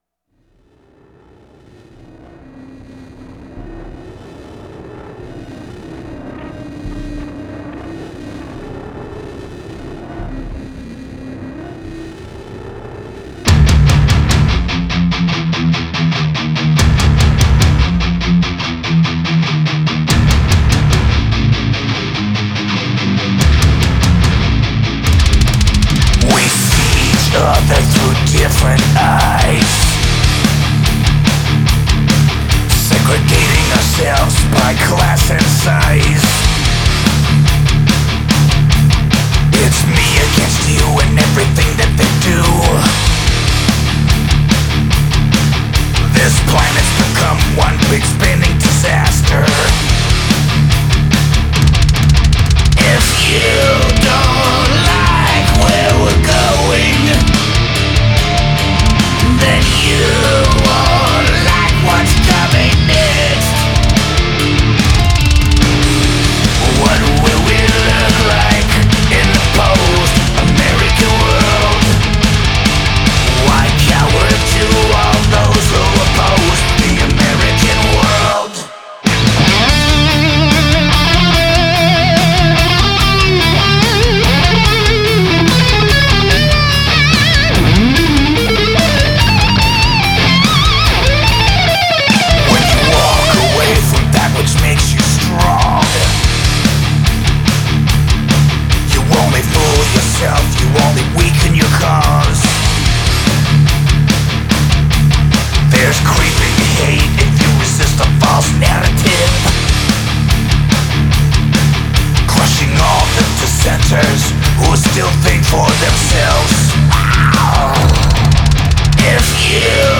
trash metal heavy metal
هوی متال